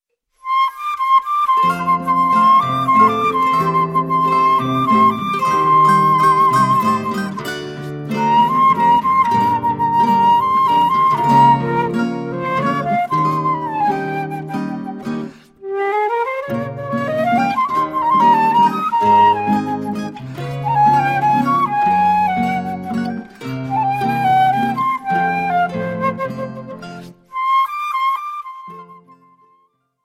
flute
Choro ensemble